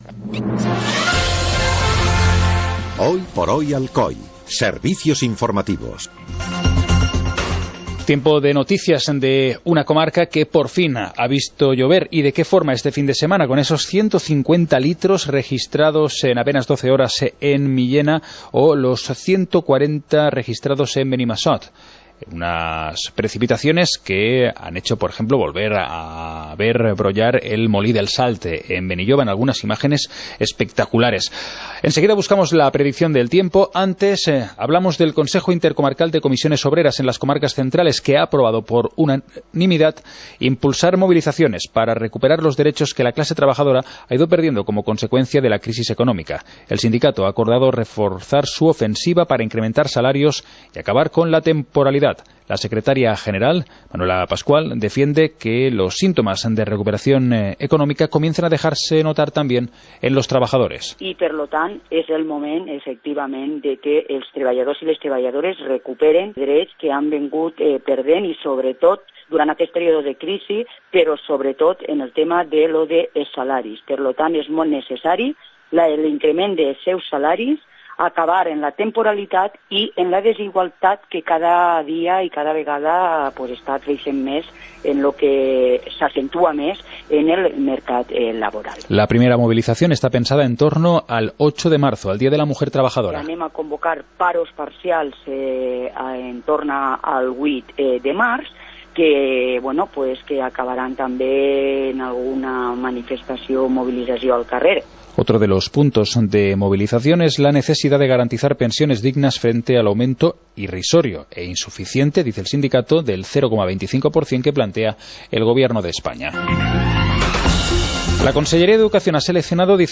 Informativo comarcal - lunes, 29 de enero de 2018